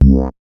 MoogVoice 014.WAV